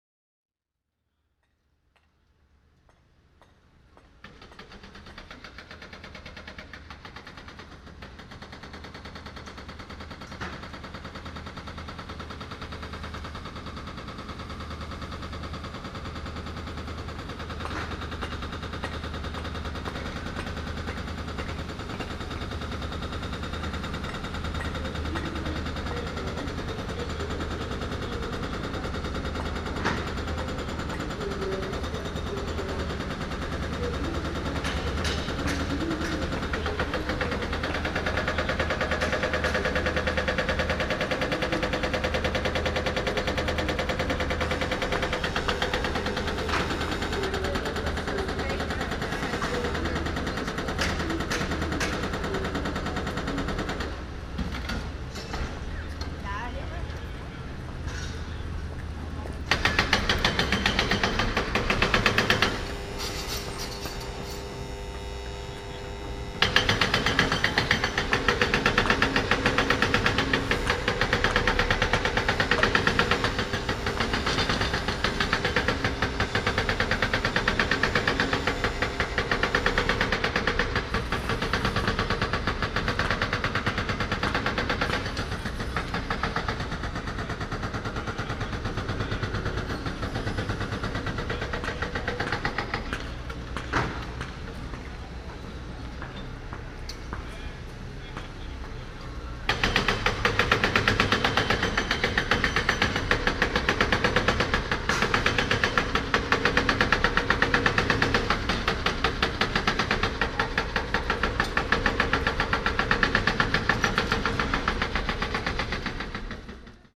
For the recording I used a directional microphone, a contact microphone, an ultrasonic detector (bats) and a binaural microphone.